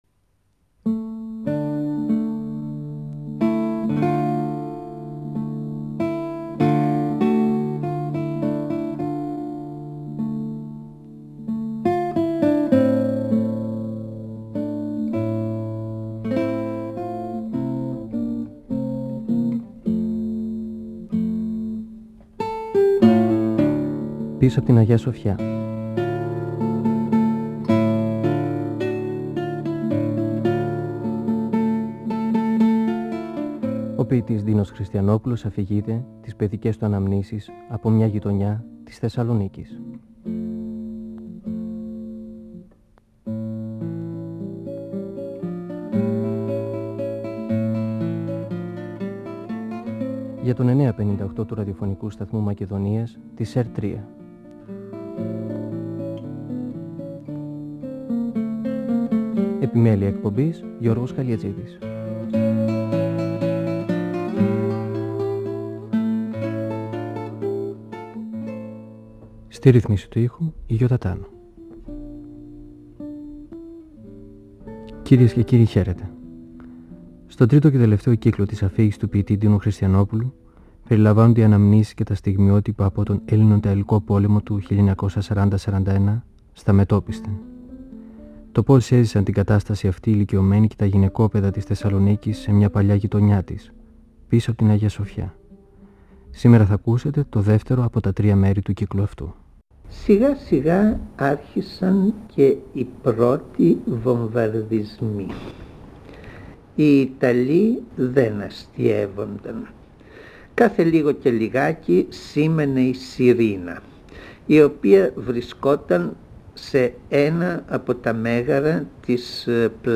(Εκπομπή 13η) Ο ποιητής Ντίνος Χριστιανόπουλος (1931-2020) μιλά για τις αναμνήσεις του από το μια παλιά γειτονιά της Θεσσαλονίκης, πίσω απ’ την Αγια-Σοφιά. Για τον βομβαρδισμό της πόλης και τον συνωστισμό στα καταφύγια.